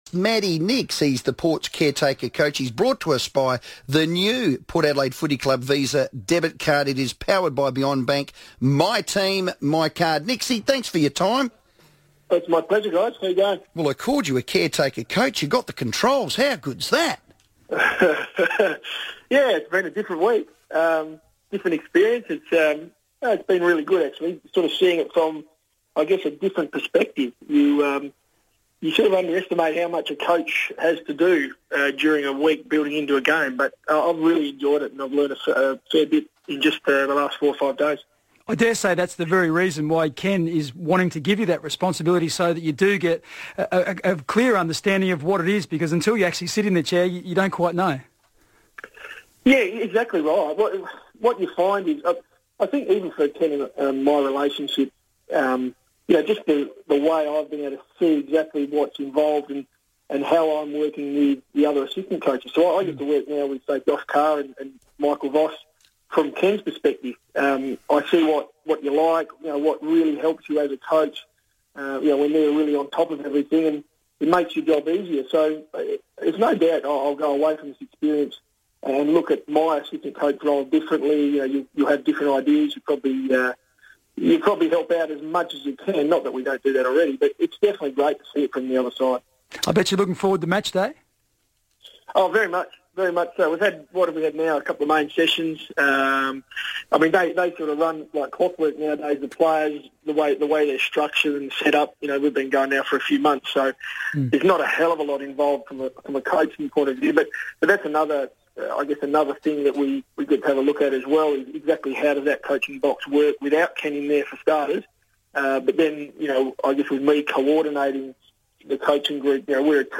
Matthew Nicks FIVEaa interview - Thursday 5th March, 2015